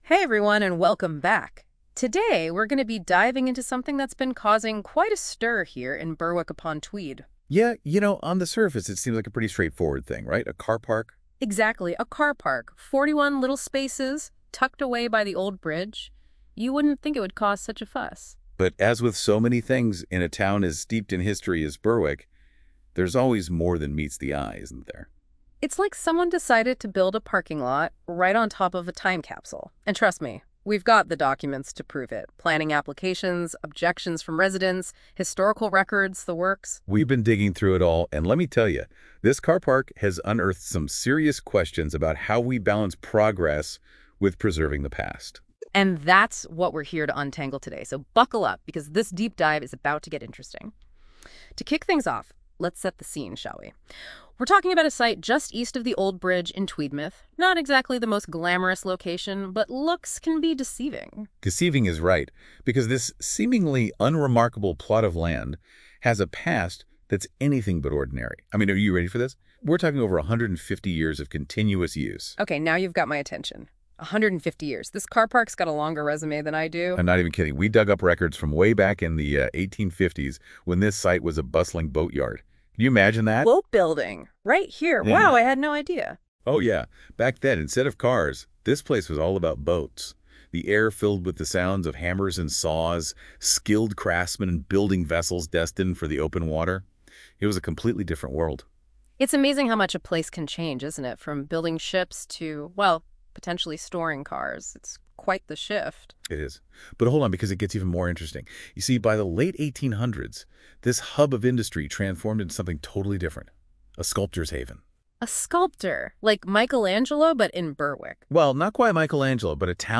Listen to an audio summary of the issues, generated by NotebookLM from our FAQ - please excuse pronunciations.